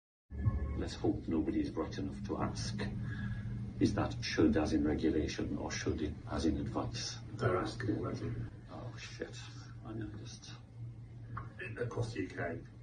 Der walisische Premierminister Mark Drakeford wurde am offenen Mikrofon erwischt: Hoffen wir, dass die Leute nicht schlau genug sind, zu fragen, ob es eine Verordnung oder ein Empfehlung ist!